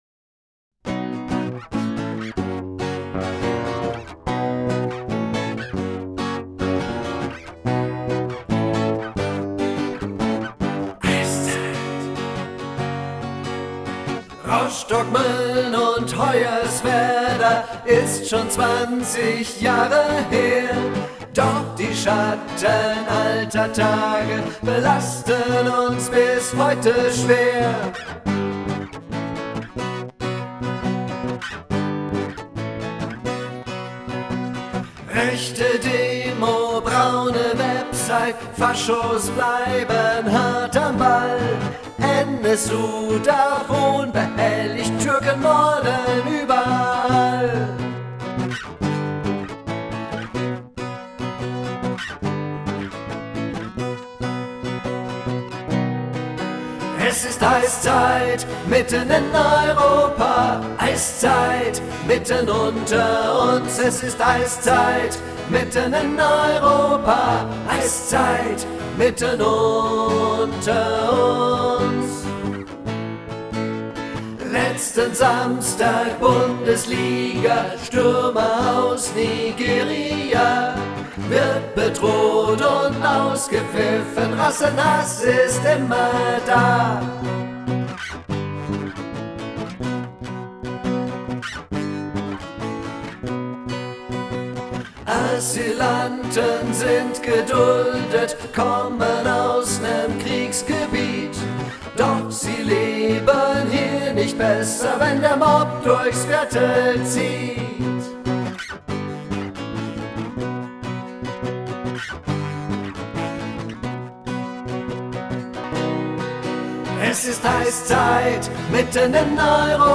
akustik-Version